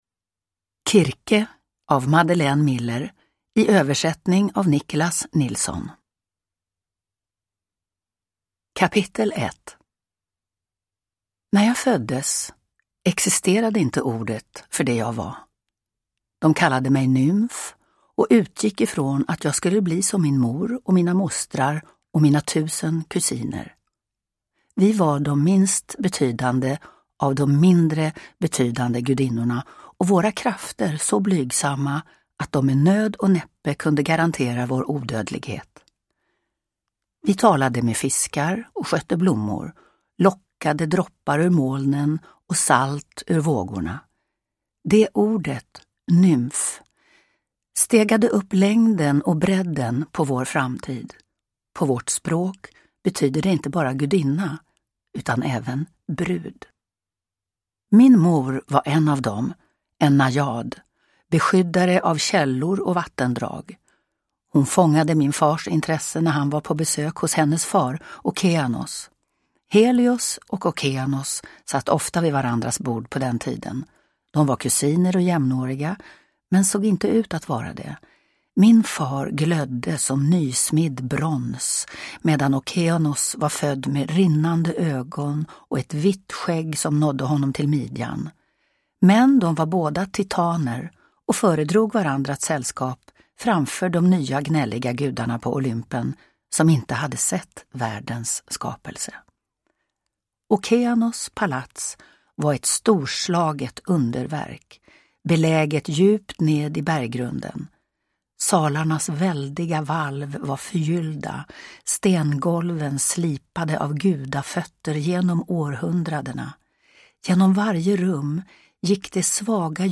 Kirke – Ljudbok – Laddas ner
Uppläsare: Lena Endre